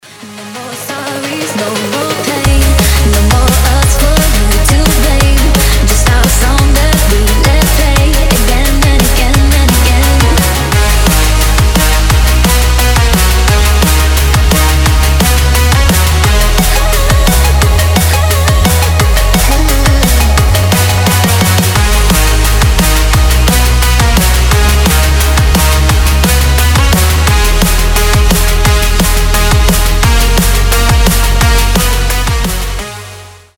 • Качество: 320, Stereo
женский голос
Electronic
драм энд бейс
Бодрый drum&bass на телефон